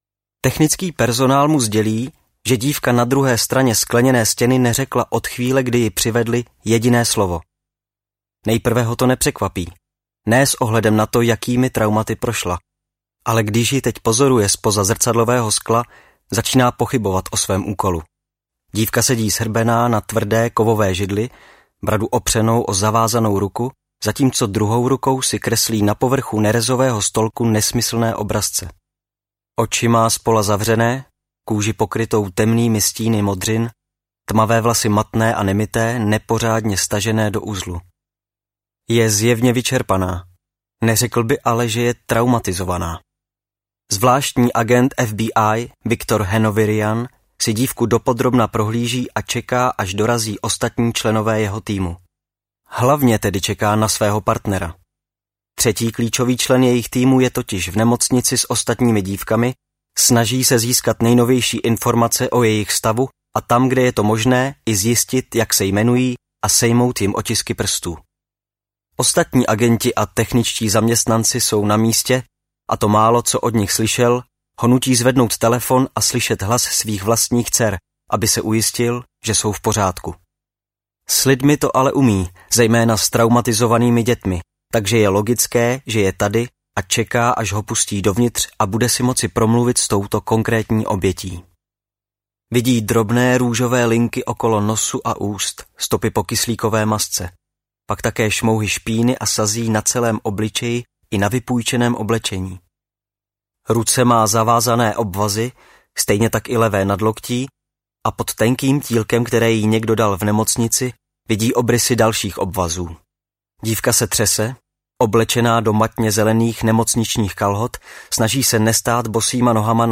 Sběratel motýlů audiokniha
Ukázka z knihy
sberatel-motylu-audiokniha